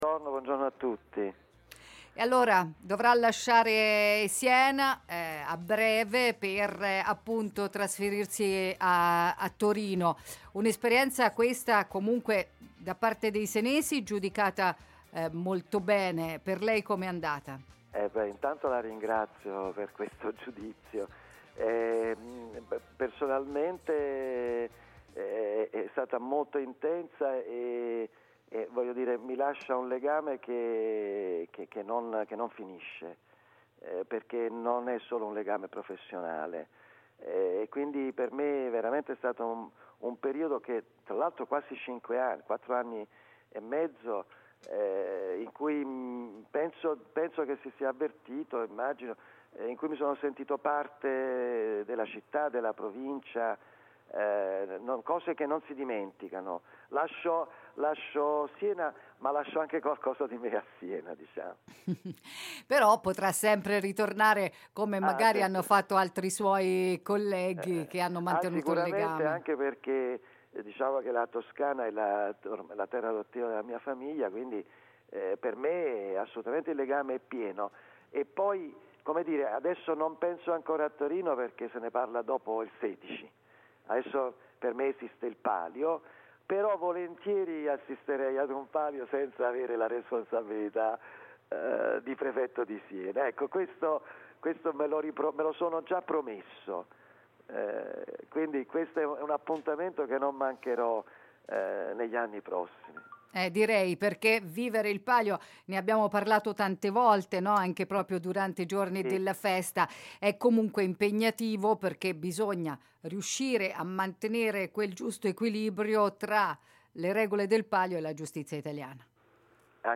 Impegno e partecipazione totale per il Prefetto Saccone, che intervistato questa mattina ai nostri microfoni ha rivelato di voler tornare a Siena in altre vesti: “vorrei vedere il Palio senza avere la responsabilità di essere il prefetto della Città, me lo sono già riproposto.”